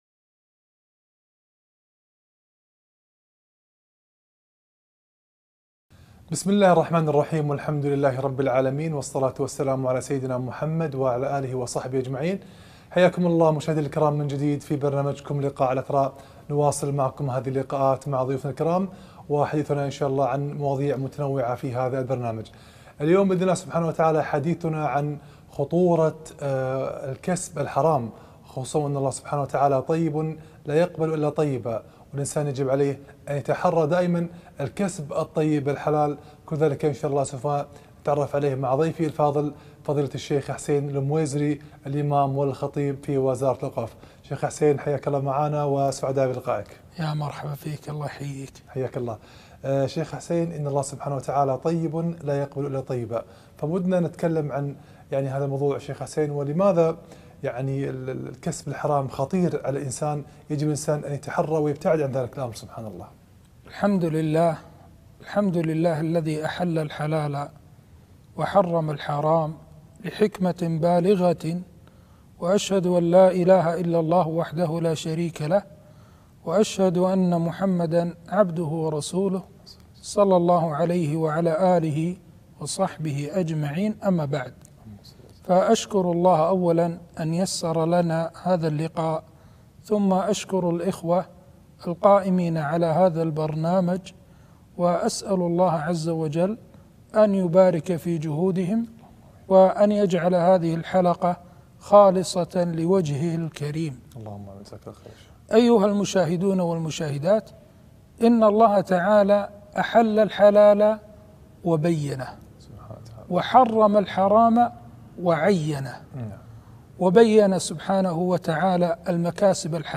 إن الله طيب لايقبل إلا طيبا - لقاء إذاعي قناة إثراء الفضائية